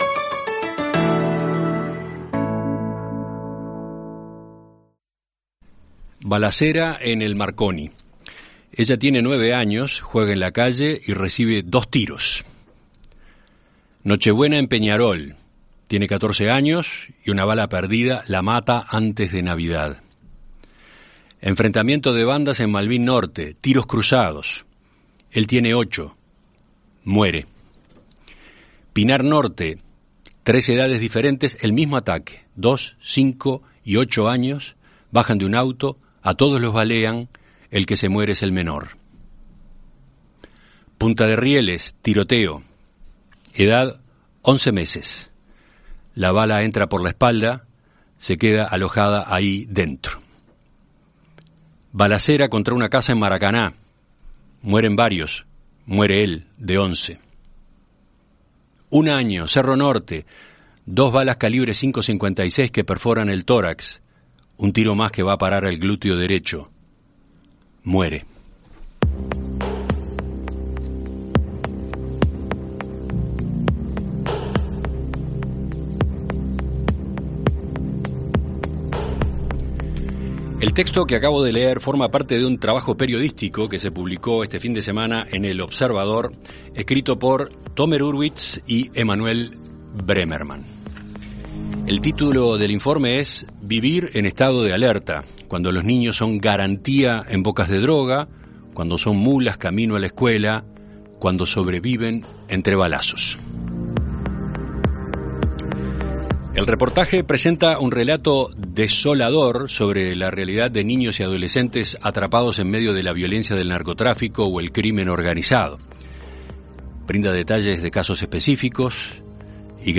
En Perspectiva Zona 1 – Entrevista Central: Mariela Solari - Océano
Conocemos a Mariela Solari, directora de la Unidad de Víctimas y Testigos de la Fiscalía General de la Nación.